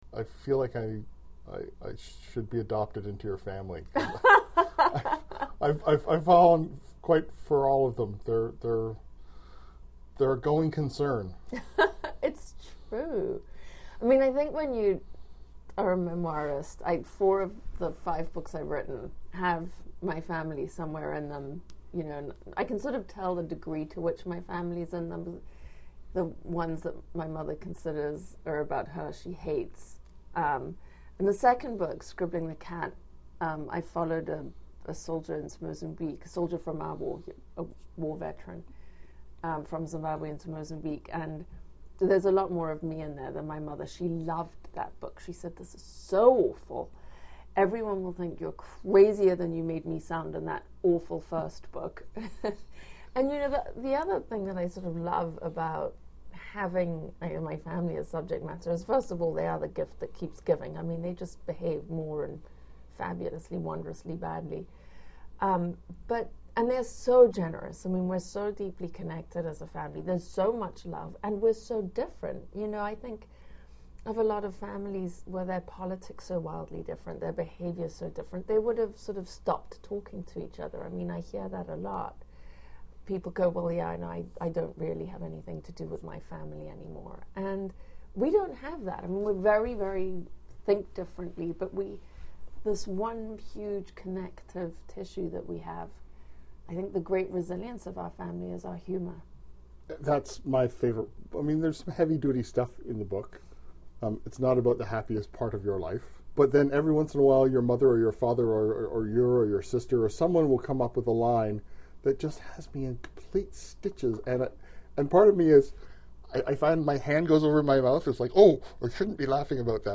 Featured Speakers/Guests: Memoirist Alexandra Fuller
Type: Interview